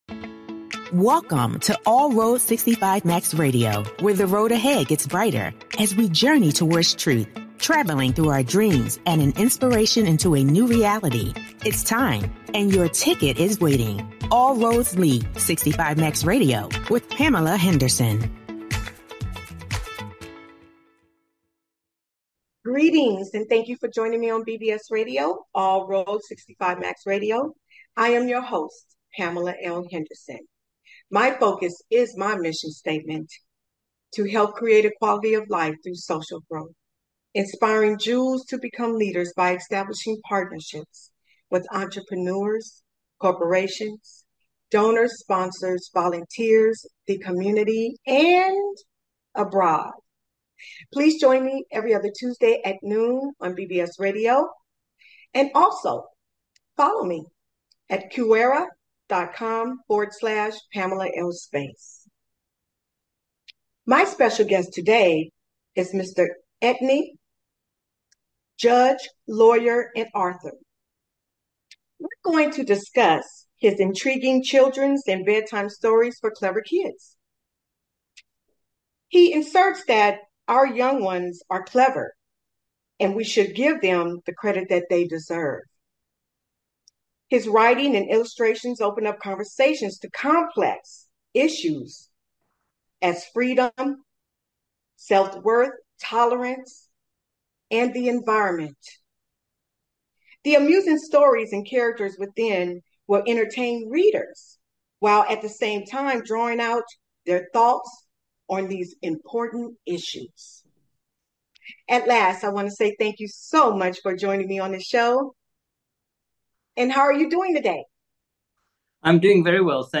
Talk Show Episode, Audio Podcast, All Roads Lead 65 Max Radio and Guest